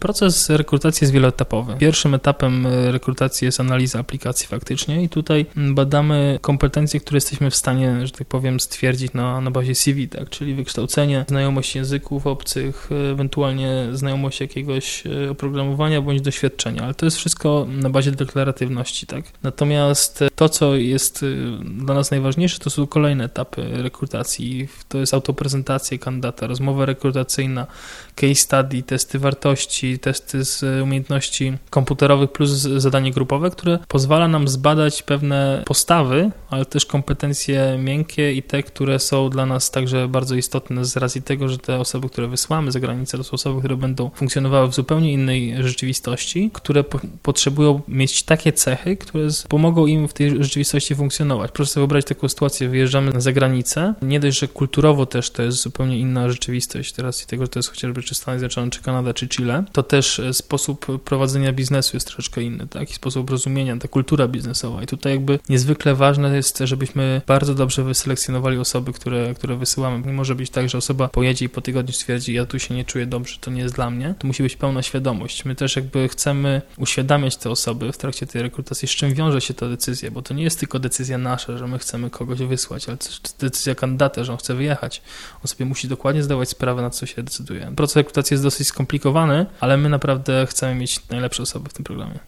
Audycja w Radiu Luz o możliwościach praktyk w KGHM to część większego projektu rozgłośni.